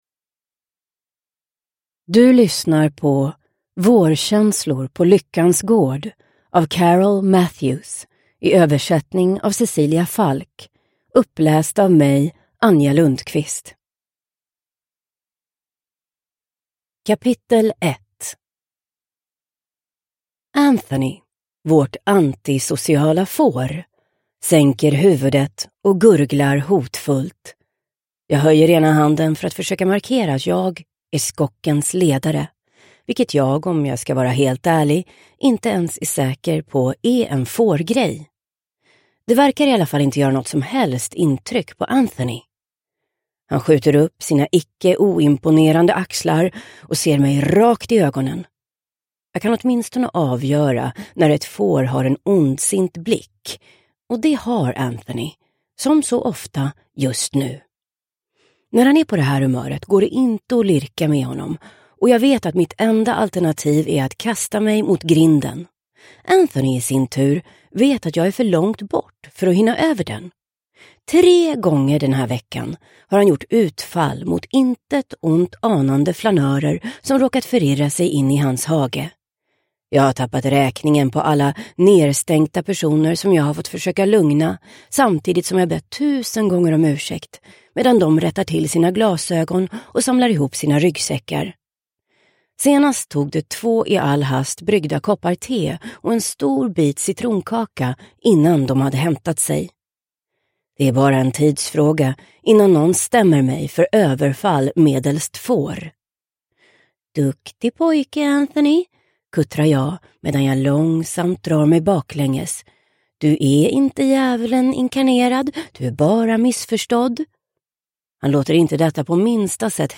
Vårkänslor på Lyckans Gård – Ljudbok – Laddas ner